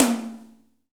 TOM GRINDE0A.wav